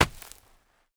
Heavy (Running)  Dirt footsteps 1.wav